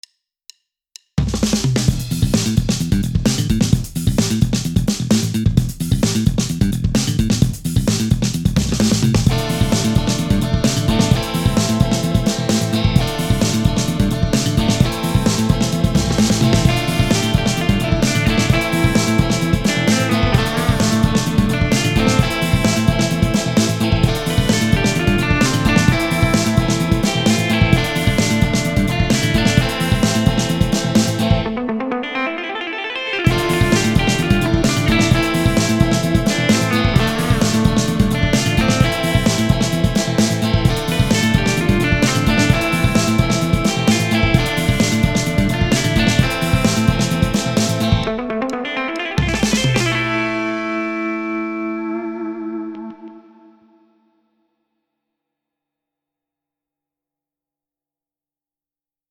Buyrun hacılar sizler için taze taze döktürdüm :) Bu aralar gitar vst'lerine takmış durumdayım ama bass olayını da unutmadığımı bu parçayla gösteriyorum :))